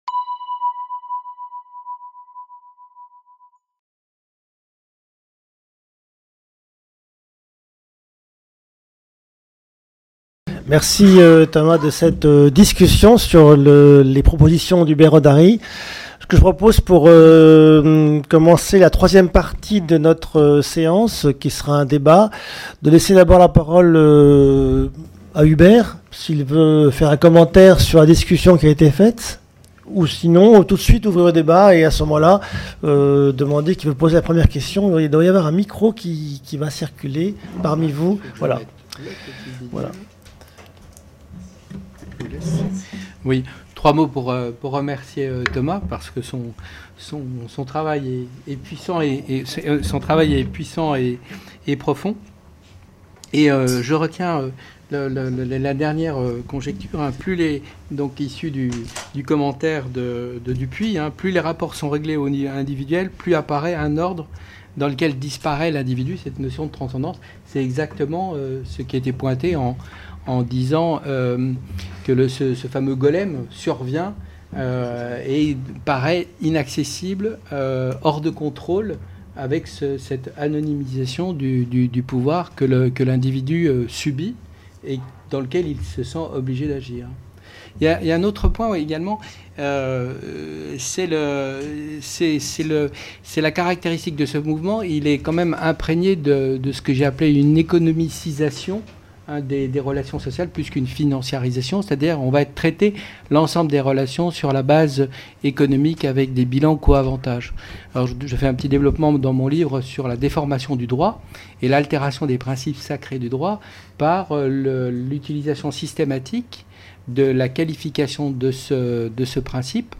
3- QUESTIONS DU PUBLIC - RÉGULATION FINANCIÈRE ET CONCEPTS CYBERNÉTIQUES / FINANCIAL REGULATION AND CYBERNETICS | Canal U